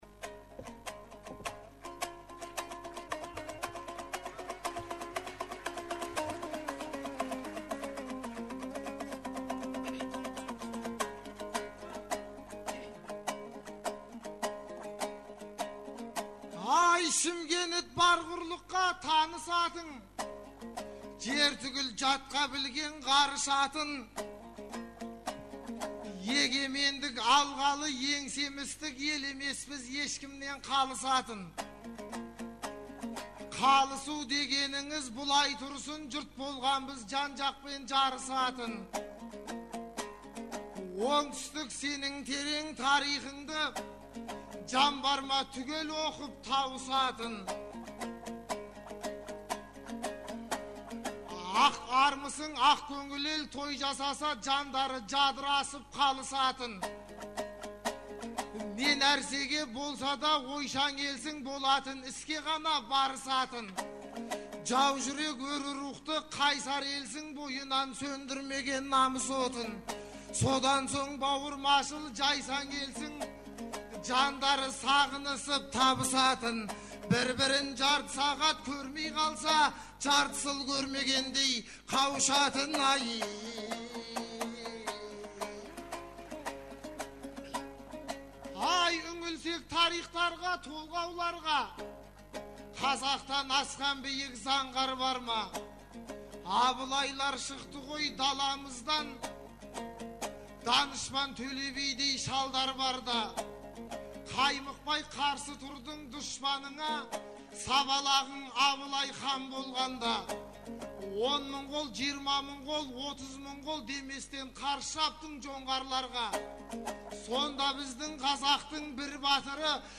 «Наурыз» айтысы
Наурыздың 15-16-сы күндері Шымкент қаласында республикалық «Наурыз» айтысы өтті. 2004 жылдан бері өтіп келе жатқан бұл айтыс биыл Төле бидің 350 жылдығына, Абылай ханның 300 жылдығына арналды.